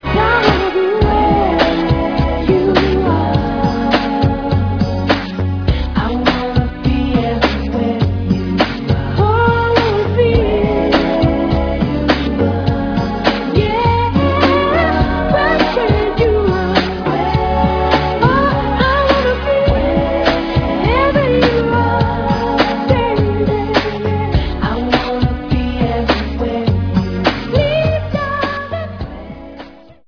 background vocals, synthesizers and drum programming
harmonica